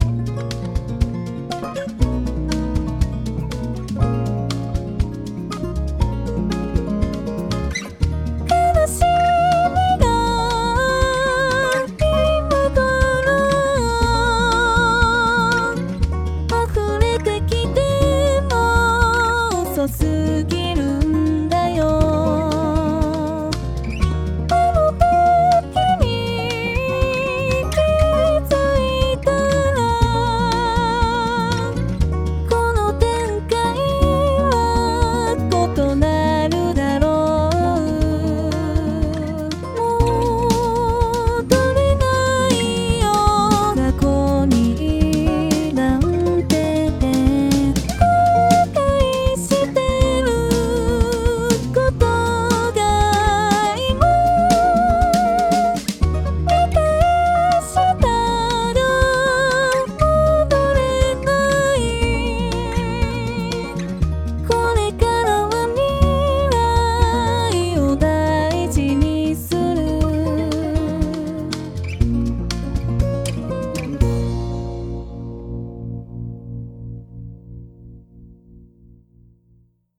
歌(104曲)